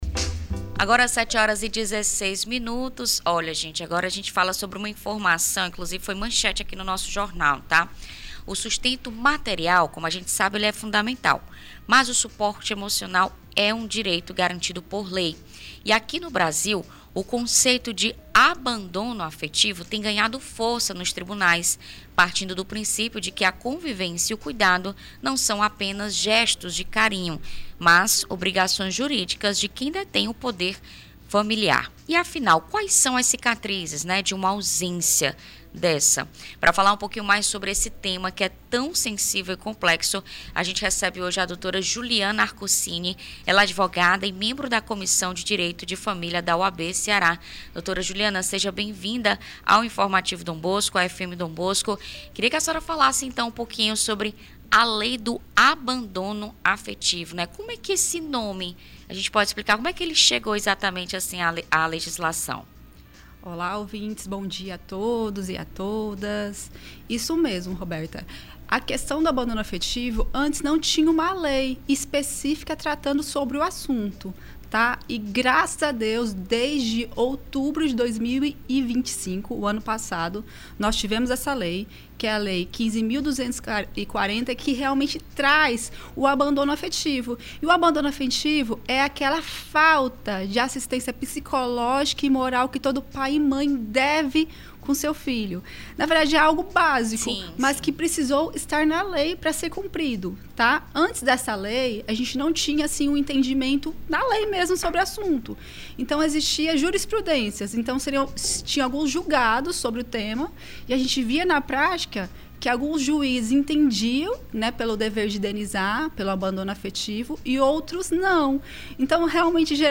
Você sabia que o abandono afetivo pode gerar indenização? Confira entrevista